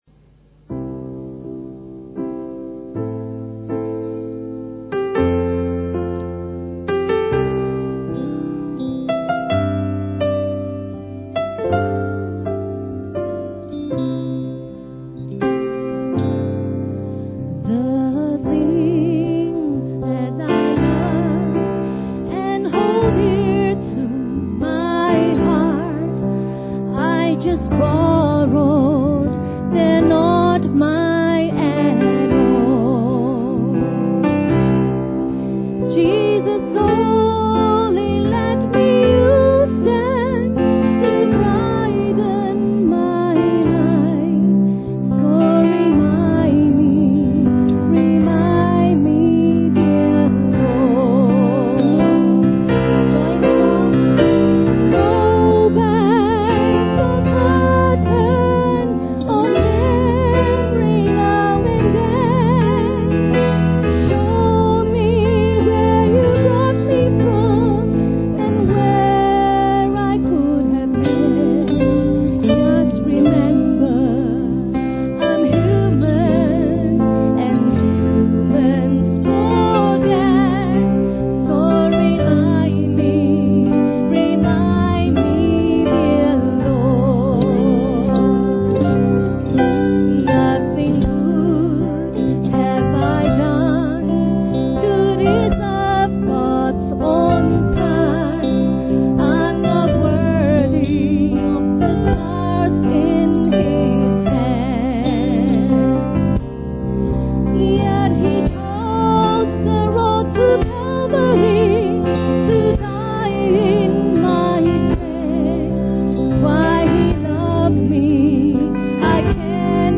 This weekend, at our 30th Anniversary celebrations, BBTC rejoiced in God’s faithfulness over the years.
BBTC 30th Anniversary Notes Download Audio Sermon